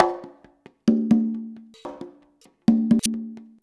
133CONGA03-L.wav